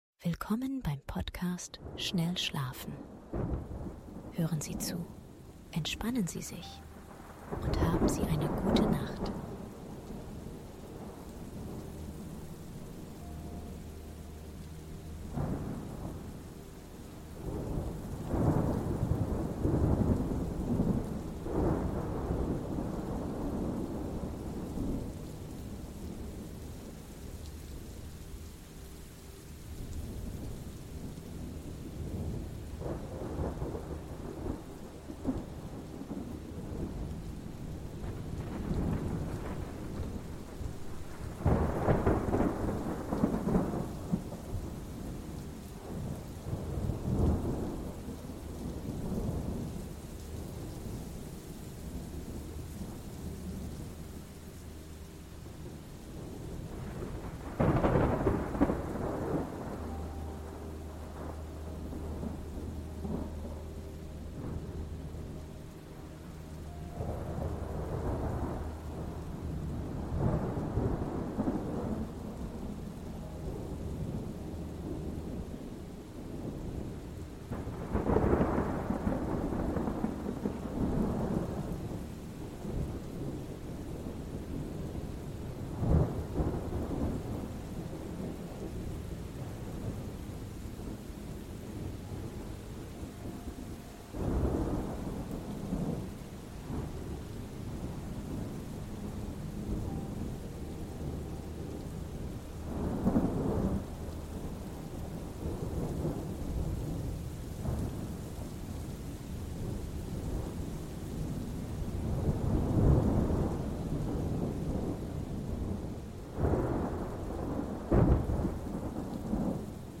⛈ Ruhige MUSIK und Melodisches GEWITTER: WOHLBEFINDEN und Qualitätsschlaf